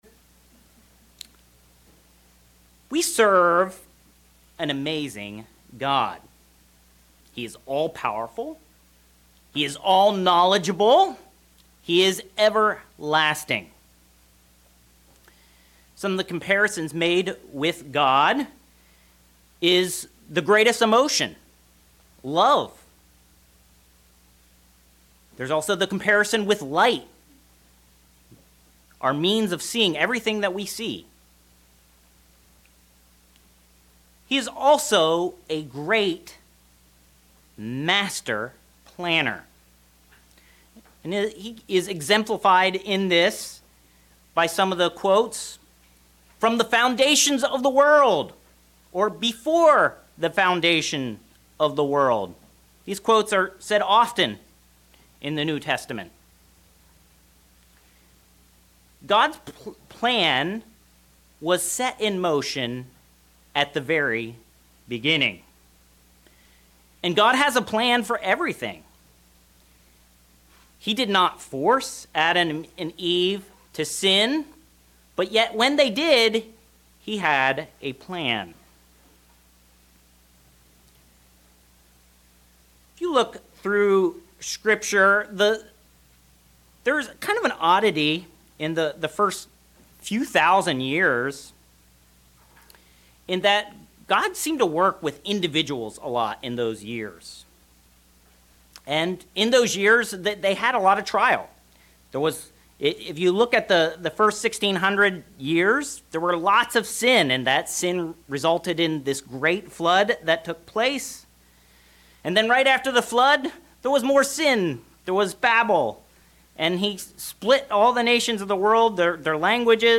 Sermons
Given in Northern Virginia